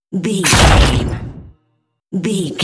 Dramatic hit bloody glass
Sound Effects
heavy
intense
dark
aggressive